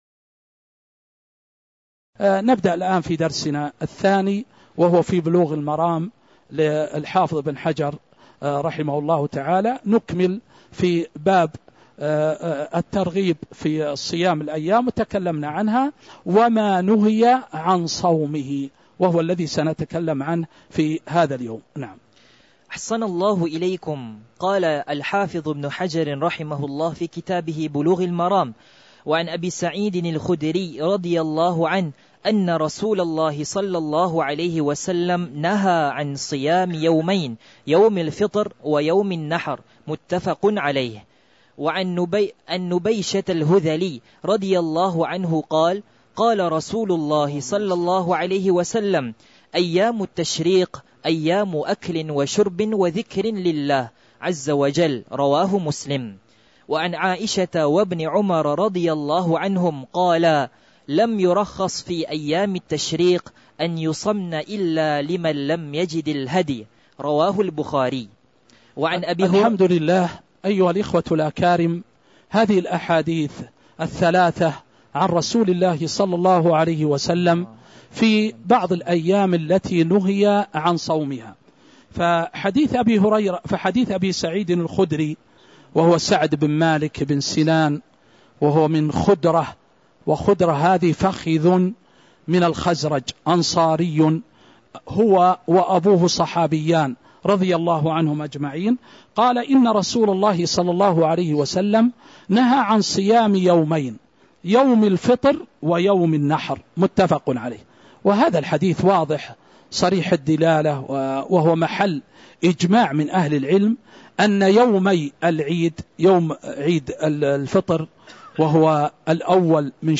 تاريخ النشر ٤ ربيع الأول ١٤٤٦ هـ المكان: المسجد النبوي الشيخ